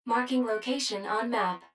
153_Marking_Location.wav